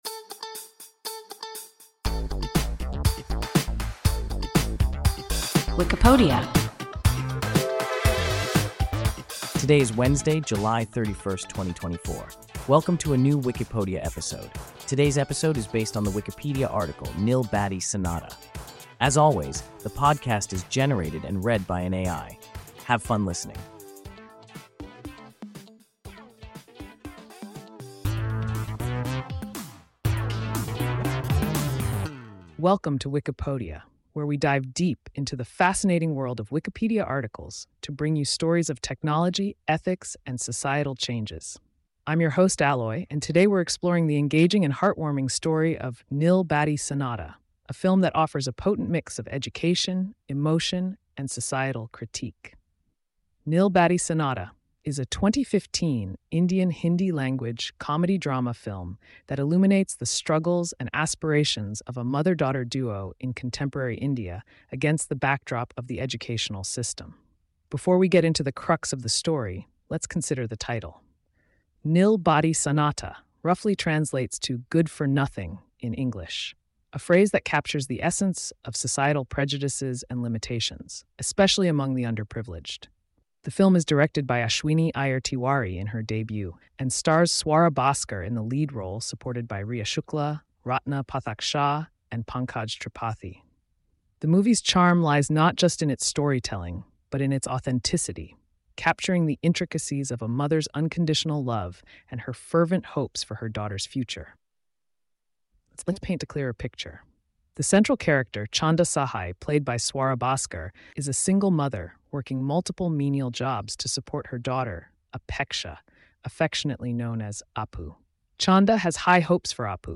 Nil Battey Sannata – WIKIPODIA – ein KI Podcast